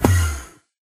portal.ogg